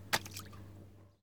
Footsteps
blood1.ogg